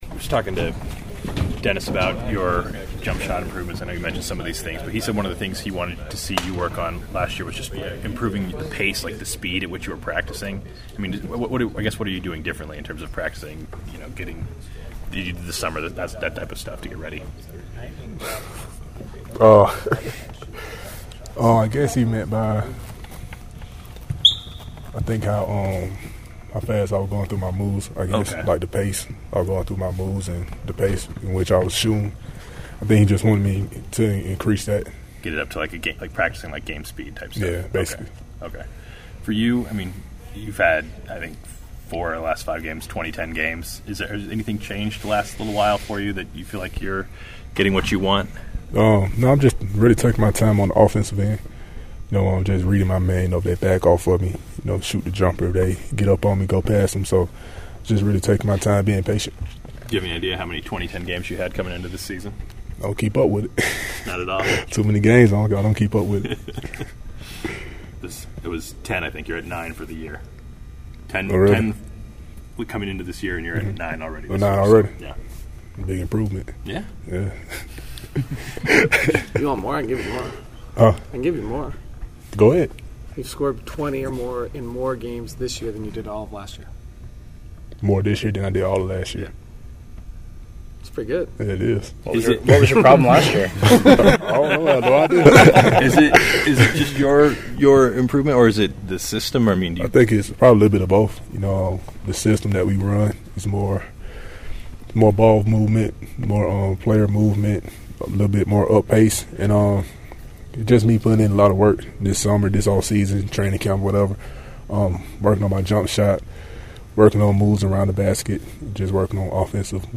Derrick Favors is playing well offensively this season as he is averaging 16.2 points per game as well as 8.6 rebounds per game. Here is Derrick Favors meeting with the media following practice today: